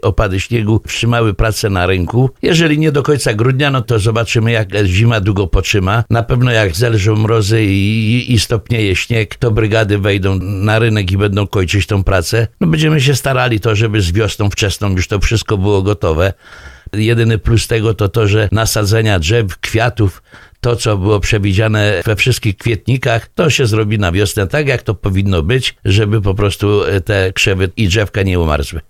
mówi burmistrz Wojnicza Tadeusz Bąk